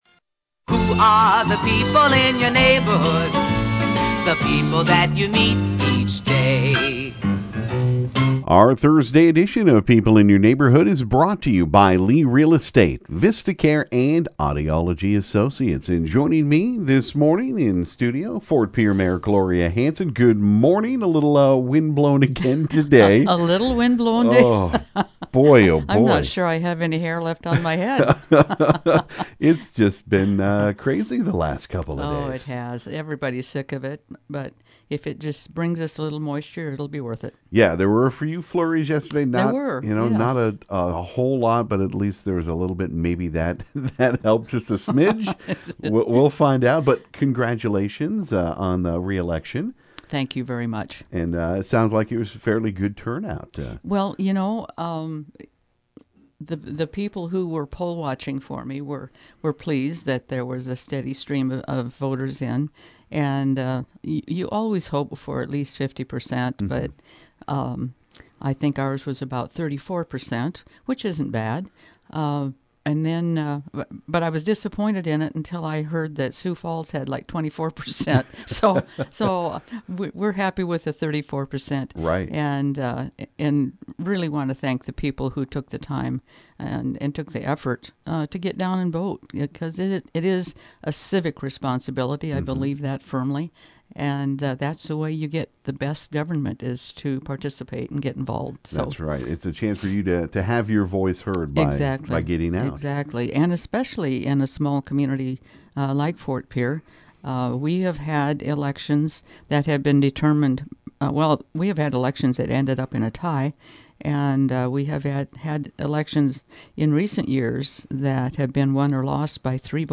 This morning newly re-elected Ft. Pierre Mayor Gloria Hanson visited KGFX and recapped the election and thanked those who got out an voted. Now it’s back to work on the many projects coming to Fort Pierre.